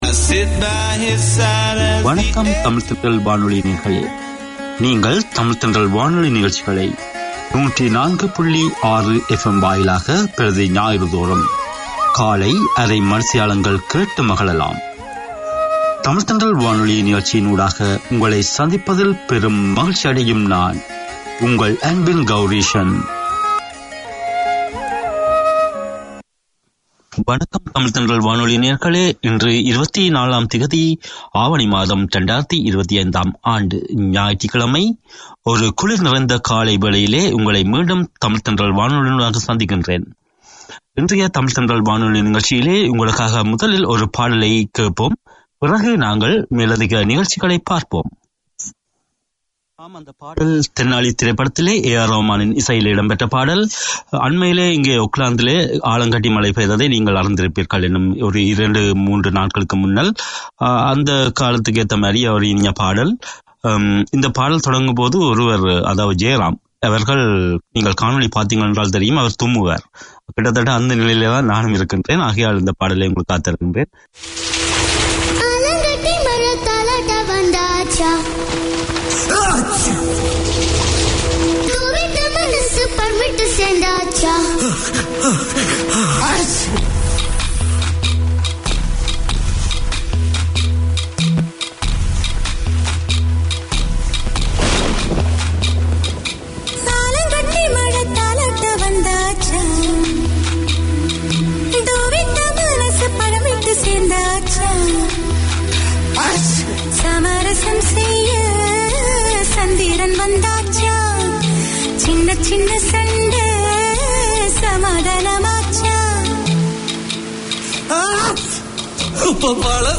An outreach of the NZ Tamil Society, this thirty minute weekly programme features Sri Lankan and Indian news, interviews, Tamil community bulletins, political reviews from Sri Lanka. There's a wealth of cultural content with drama, stories, poems and music.